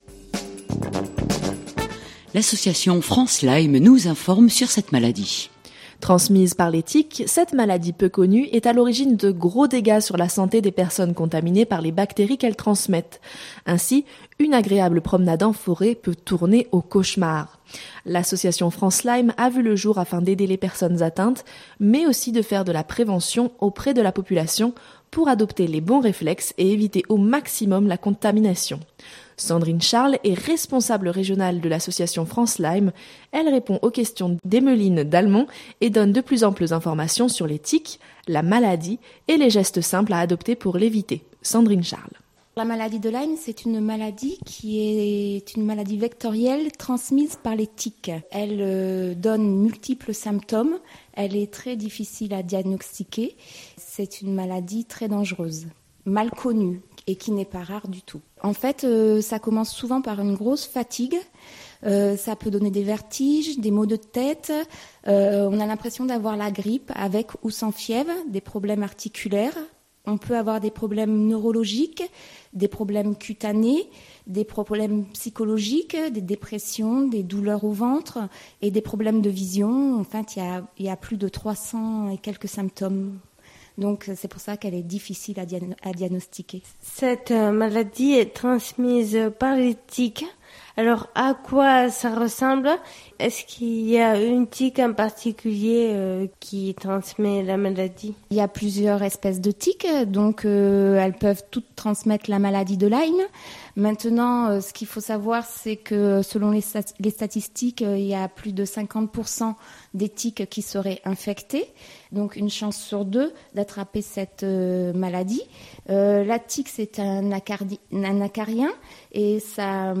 manosque_association_france_lyme.mp3 (5.26 Mo)